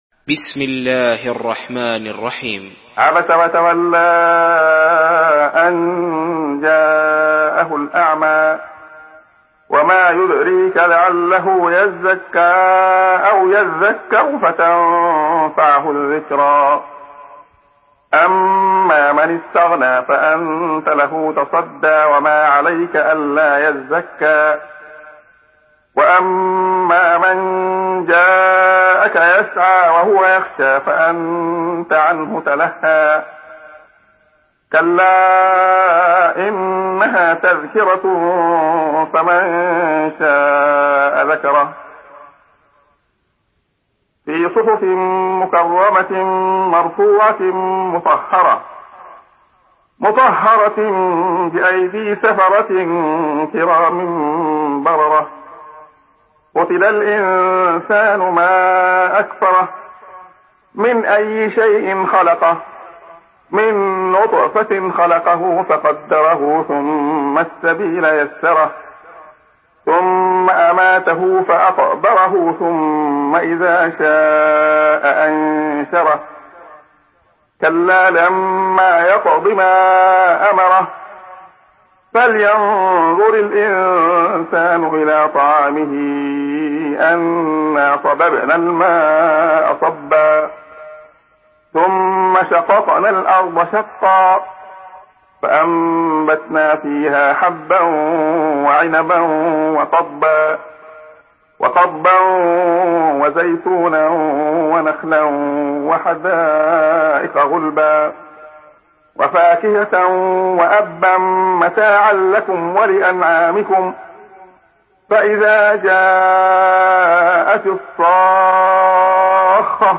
Surah Abasa MP3 in the Voice of Abdullah Khayyat in Hafs Narration
Murattal Hafs An Asim